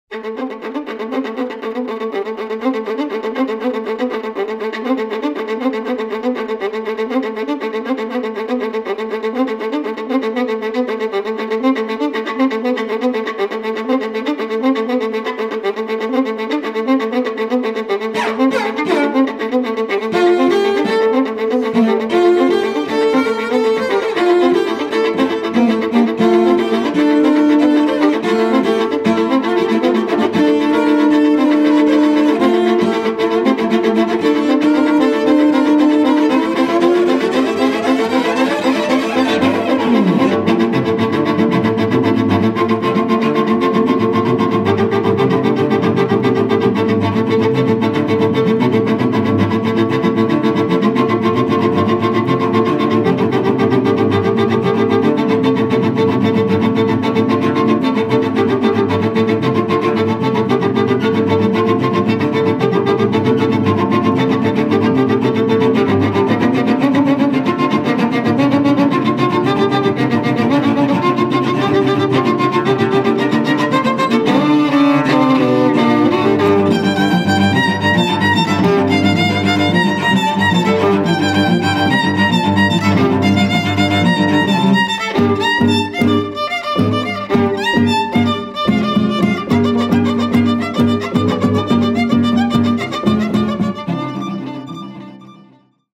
playing the violin
playing the cello.
elements of Oriental music, psychedelic rock and free jazz
improvisations on previously agreed on themes.